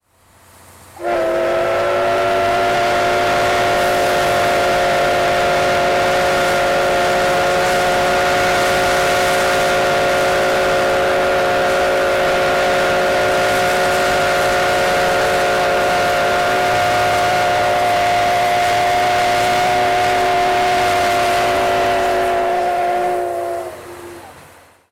C57180whistle.mp3